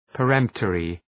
{pə’remptərı}
peremptory.mp3